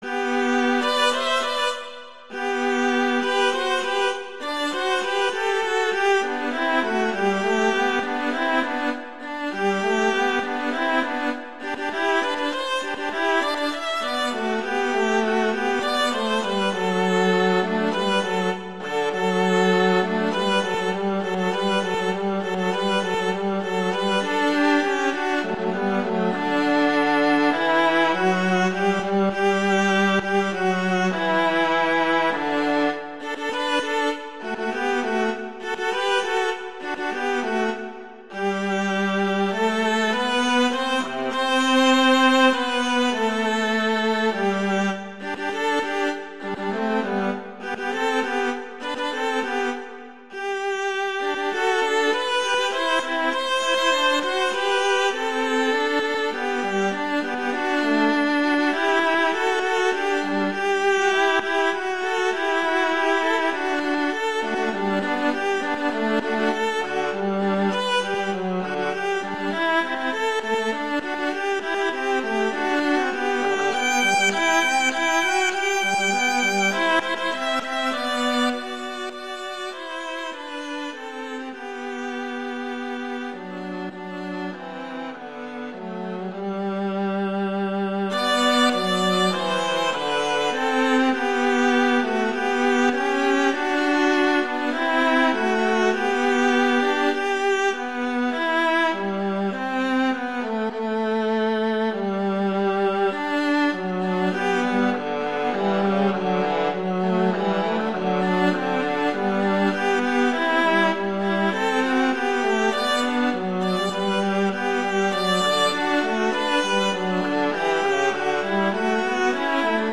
transcription for two violas
classical, sacred
G major
♩=120 BPM